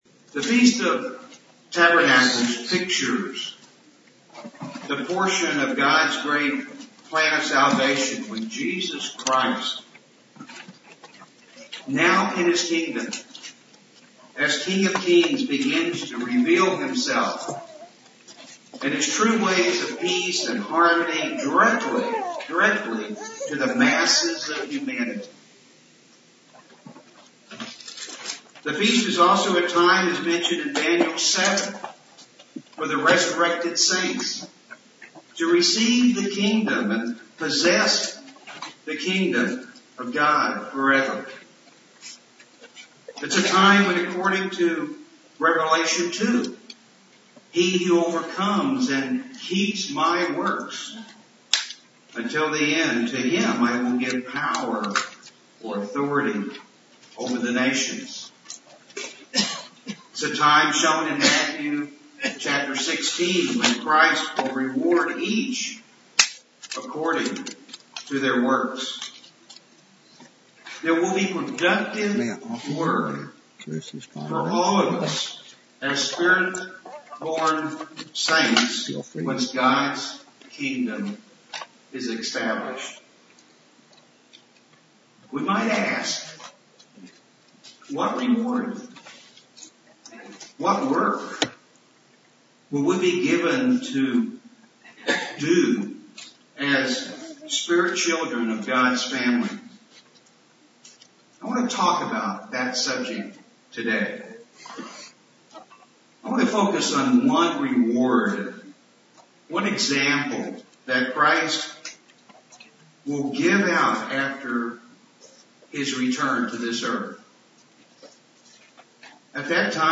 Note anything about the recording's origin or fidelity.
This sermon was given at the Branson, Missouri 2020 Feast site.